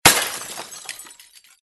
Звуки разбитого стекла, посуды
На этой странице собраны разнообразные звуки разбитого стекла и посуды: от легкого звона бокалов до резкого грохота падающих тарелок.